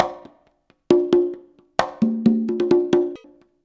133CONGA02-L.wav